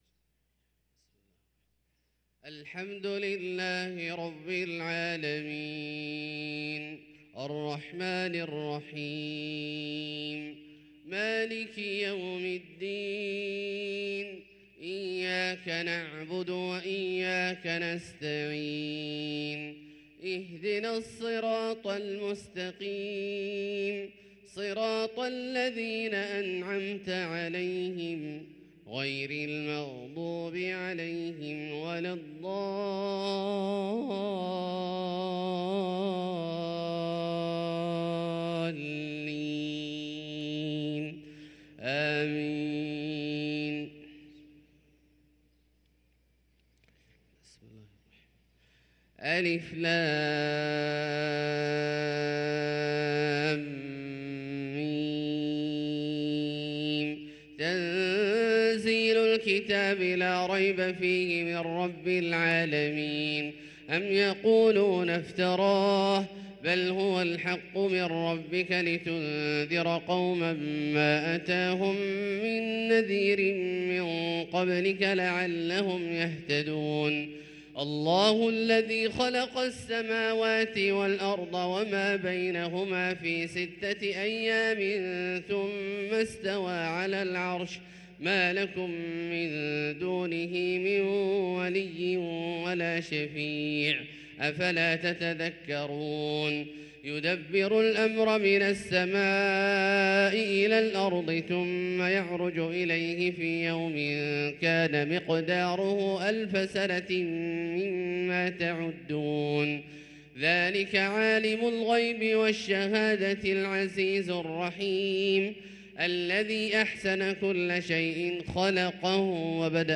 صلاة الفجر للقارئ عبدالله الجهني 27 جمادي الآخر 1444 هـ
تِلَاوَات الْحَرَمَيْن .